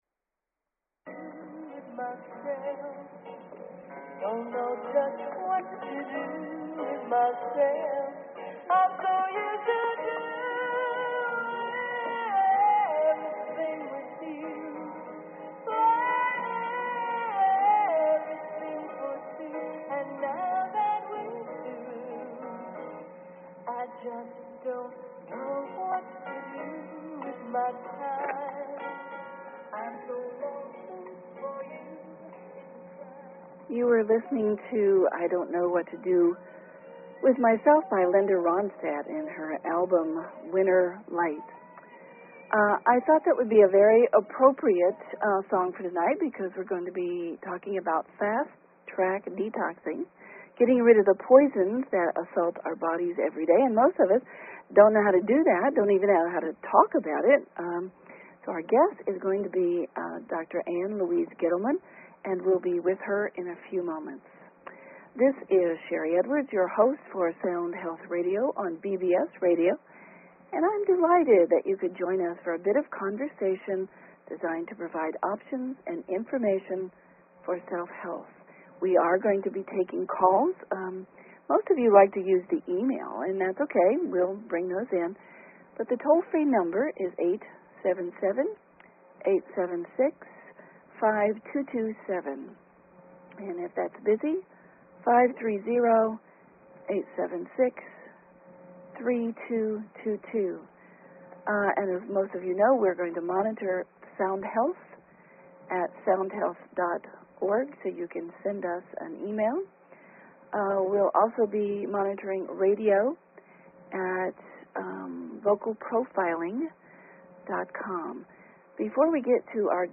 Talk Show Episode, Audio Podcast, Sound_Health and Courtesy of BBS Radio on , show guests , about , categorized as
Lots of listener questions made the show a lively interchange of information concerning fasting and thyroid, low blood sugar and menopause.